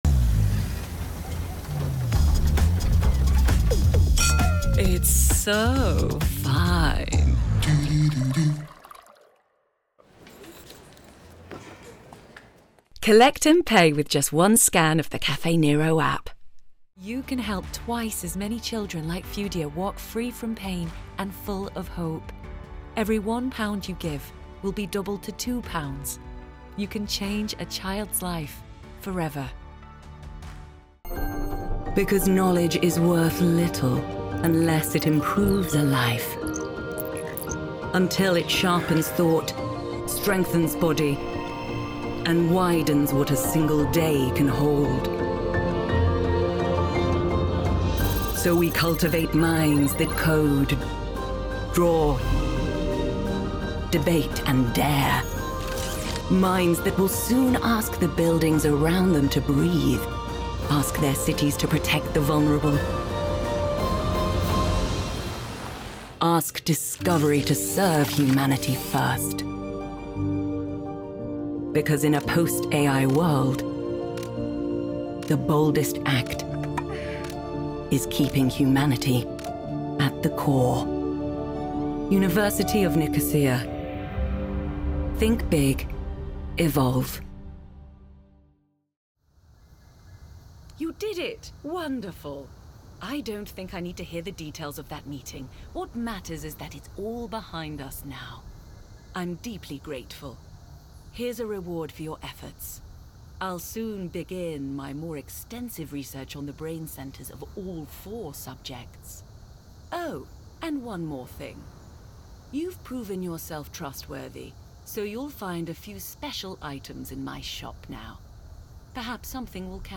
Clear, friendly British accent with husky, biscuity tones
Voiceover Demo Reel Final (audio)_0.mp3